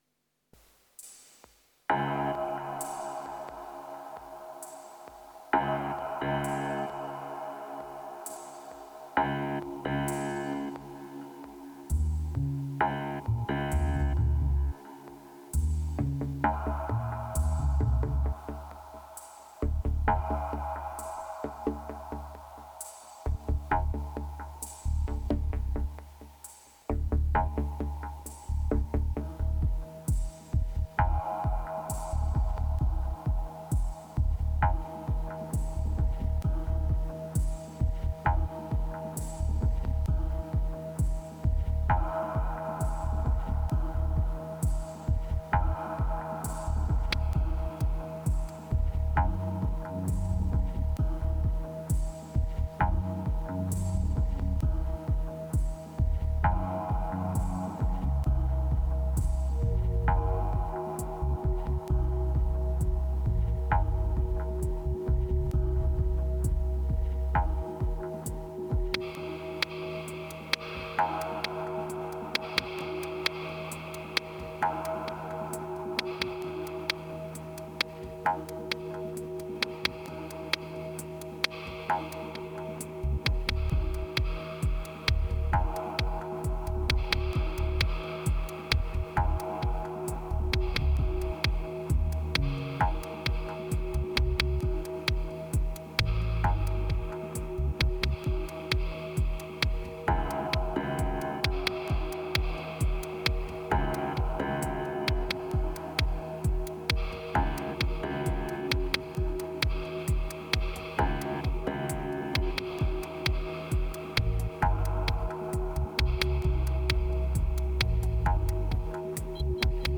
2405📈 - 1%🤔 - 66BPM🔊 - 2017-06-07📅 - -240🌟